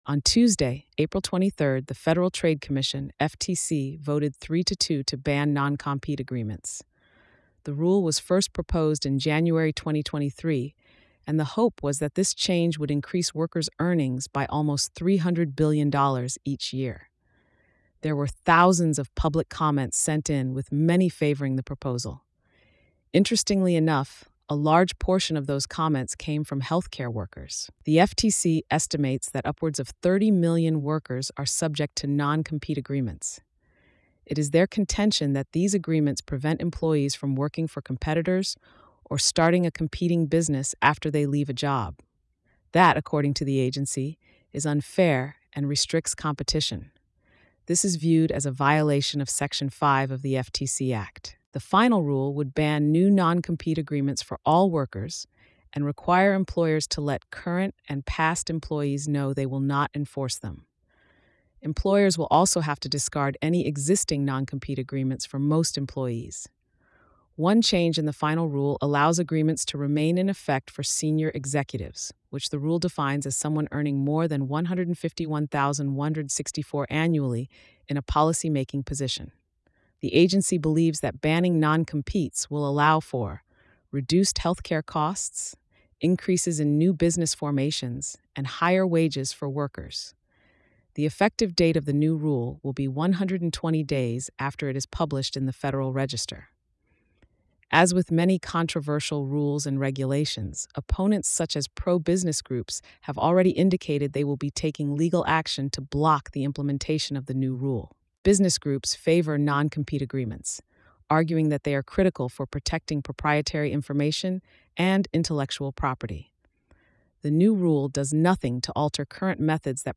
Federal Trade Commission Weighs in On Non-Competes Blog Narration.mp3